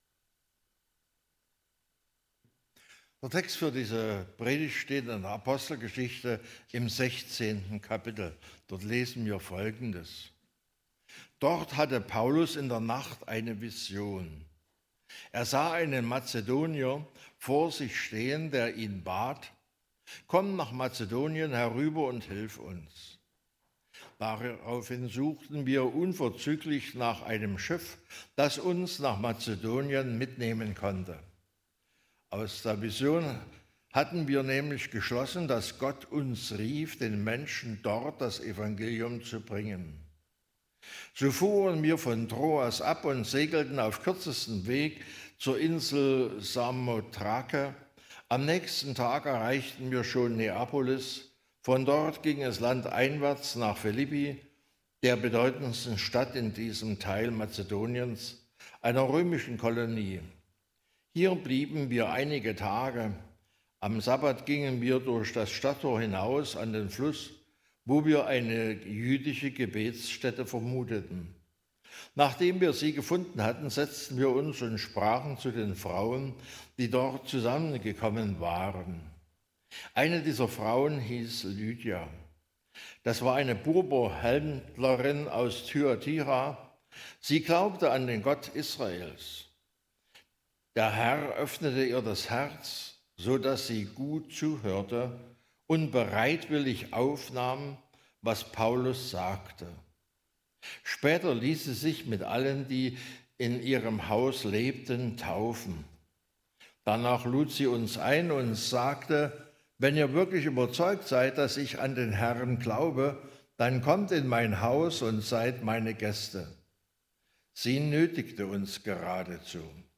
9-15 Gottesdienstart: Predigtgottesdienst Obercrinitz Paulus hat eine Vision und fährt sofort los